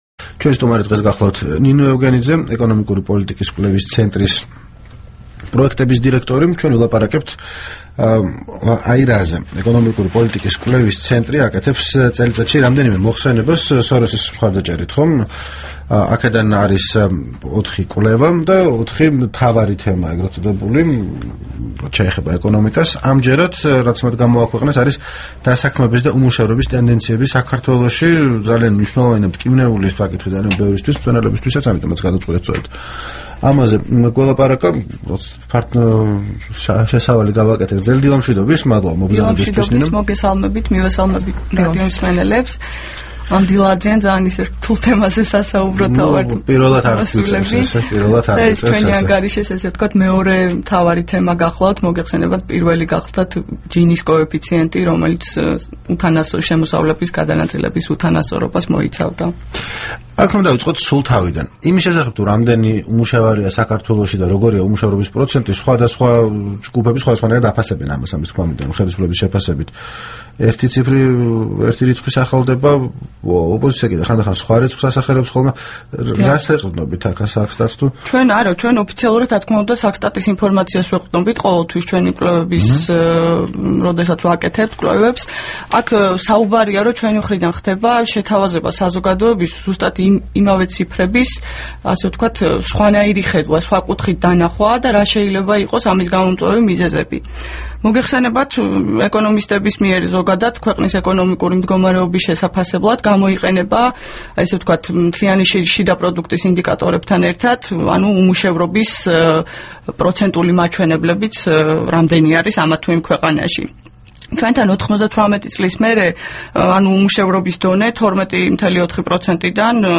სტუმრად ჩვენს ეთერში
რადიო თავისუფლების თბილისის სტუდიაში